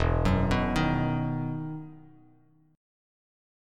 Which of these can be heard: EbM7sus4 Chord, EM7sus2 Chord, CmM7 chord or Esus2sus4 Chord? EM7sus2 Chord